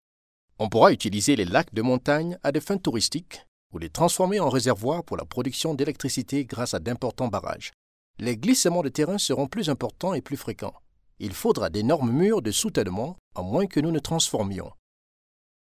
African French voice over